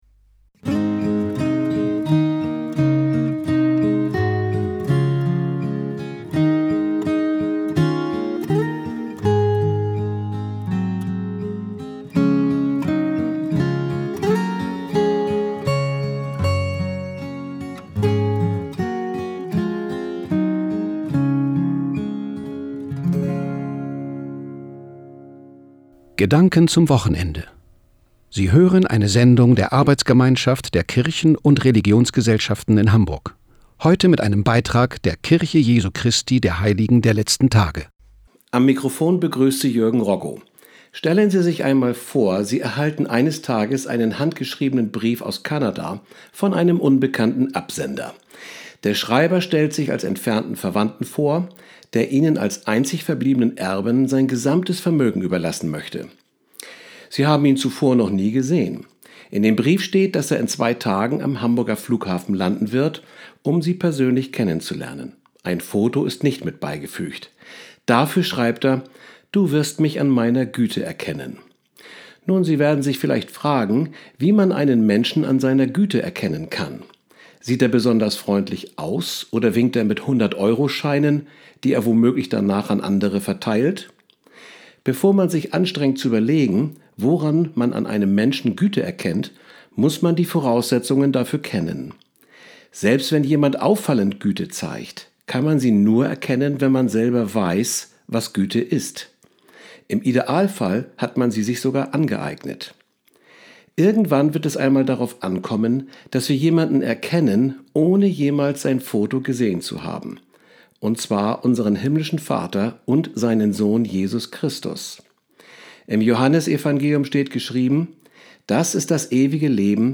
Die am Samstag, den 18. März 2017, auf dem Hamburger Bürger- und Ausbildungskanal TIDE gesendete Botschaft der Kirche Jesu Christi der Heiligen der Letzten Tage zum Thema "Jesus Christus erkennen" steht ab sofort als Podcast auf der Presseseite zur Verfügung.